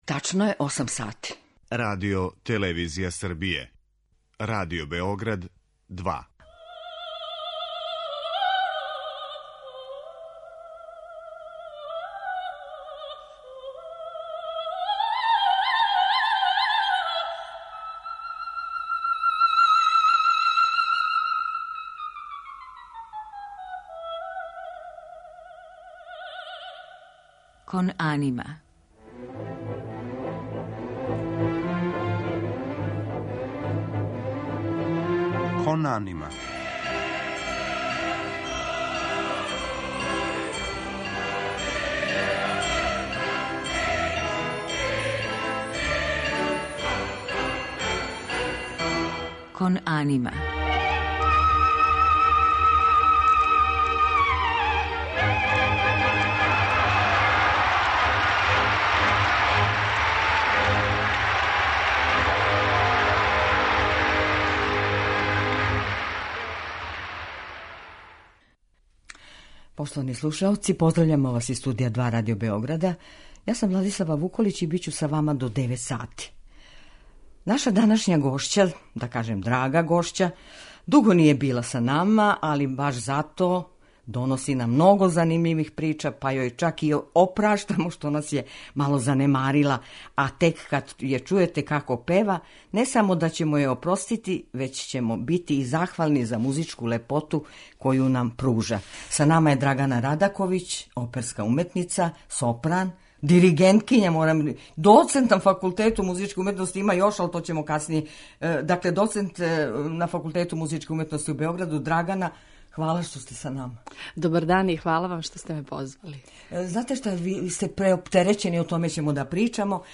У музичком делу, биће емитоване арије из опера Ђузепа Вердија и Ђакома Пучинија